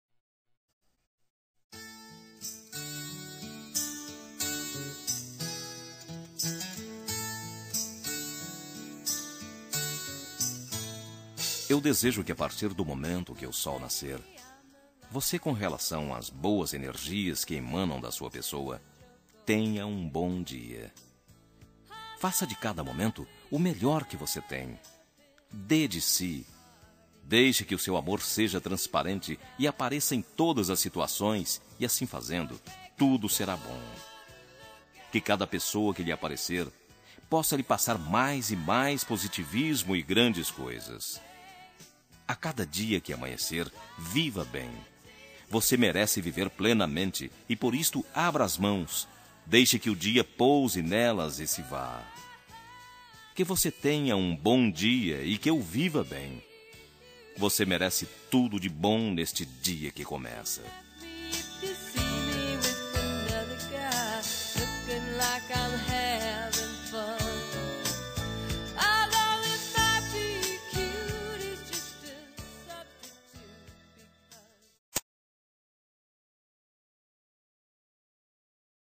Telemensagem de Bom Dia – Voz Masculina – Cód: 6326 – Geral
6326-dia-neutra-masc.mp3